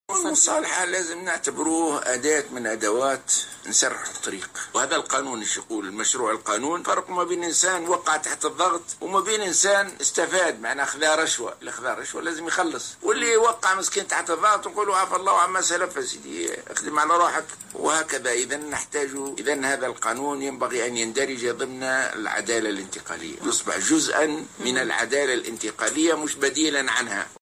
قال رئيس حركة النهضة راشد الغنوشي خلال حضوره اليوم السبت 27 أوت 2016 حفلا نظمه المكتب الجهوي للحركة بقفصة، في إطار الاحتفالات بعيد المرأة، إنّ قانون المصالحة الاقتصادية ينبغي أن يدرج ضمن العدالة الانتقالية ويصبح جزءا منها لا بديلا عنها.